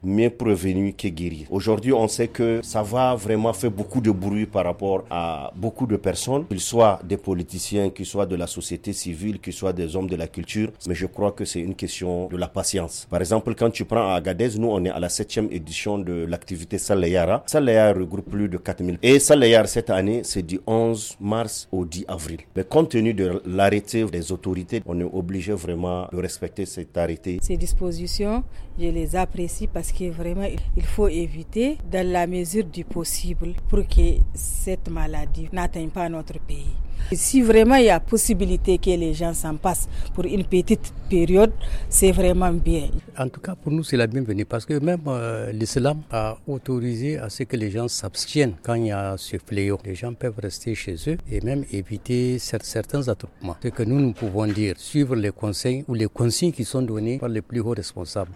Pour les citoyens rencontrés à Agadez par le Studio Kalangou, ces mesures doivent être respectées.